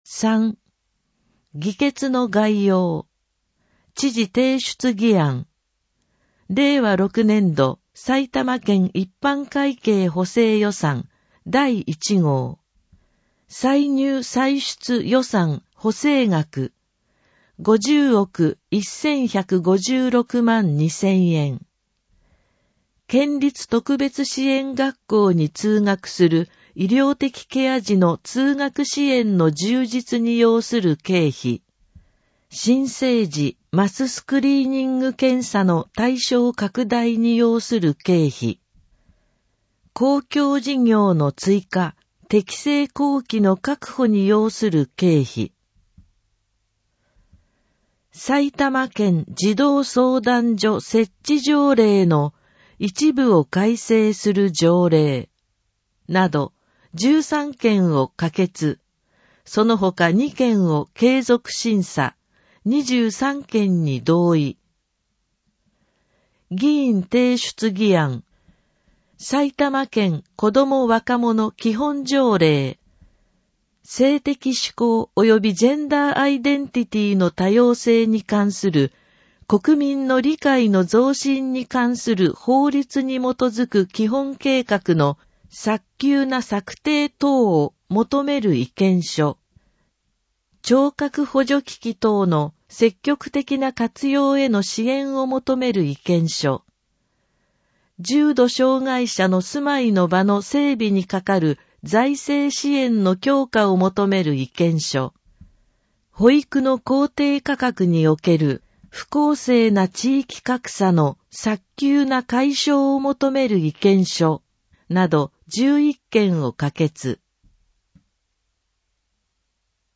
「Windows Media Player」が立ち上がり、埼玉県議会だより 179号の内容を音声（デイジー版）でご案内します。